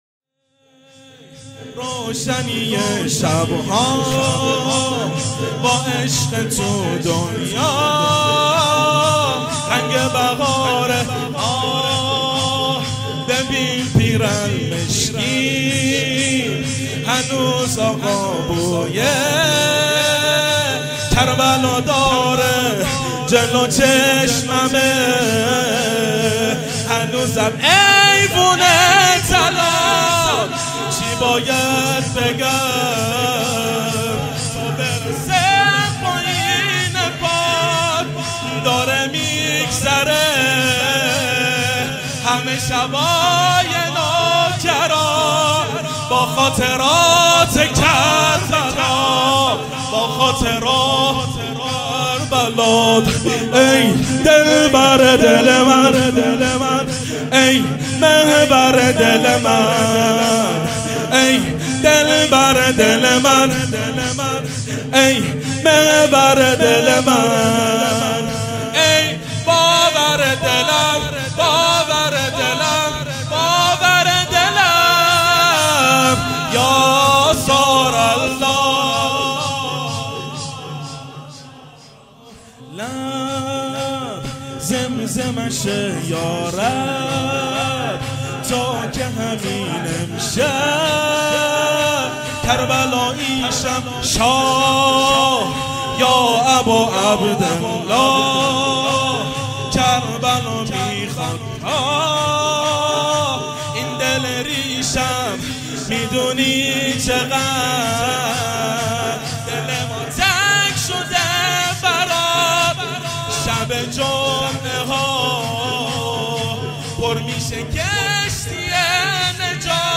شور | ببین پیرهن مشکیم هنوز آقا بوی، کربلا داره
مداحی
شب دوم محرم 1441 هجری قمری | هیأت علی اکبر بحرین